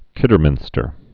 (kĭdər-mĭnstər)